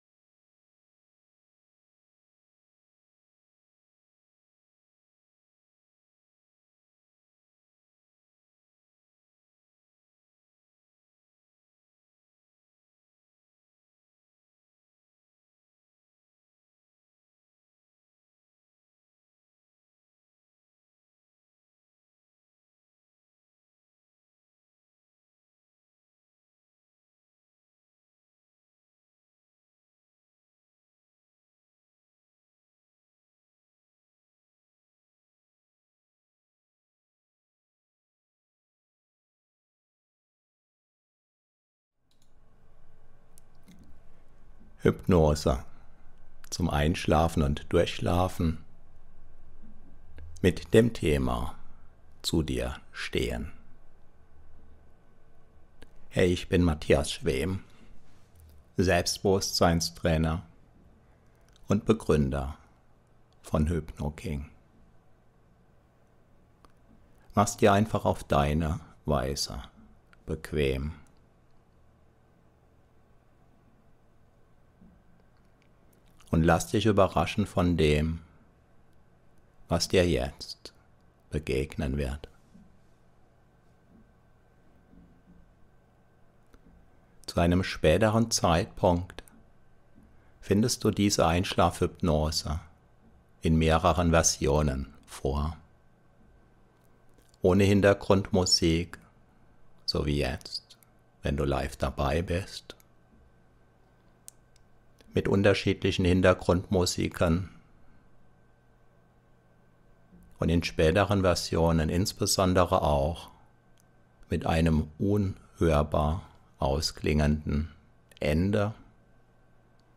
Wohl dem, der gut einschlafen kann. Diese Einschlaf-Hypnose hilft dir dabei.